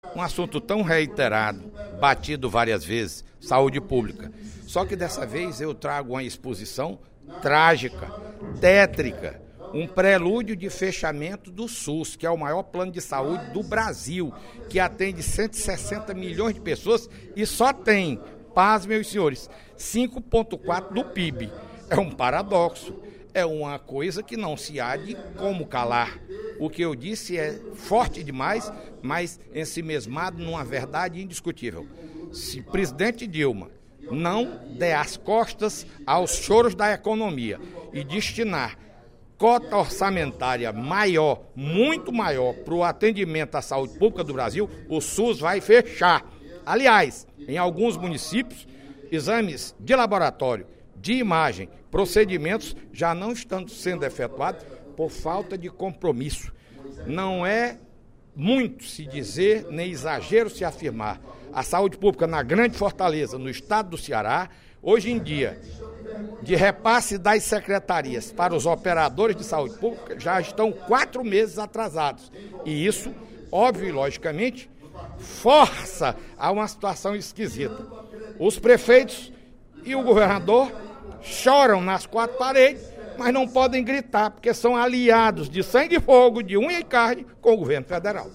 Durante o primeiro expediente da sessão plenária desta sexta-feira (06/02), o deputado Fernando Hugo (SD) demonstrou preocupação com a situação do Sistema Único de Saúde (SUS), cobrando providências urgentes do Governo Federal em relação ao aumento orçamentário para a área da saúde.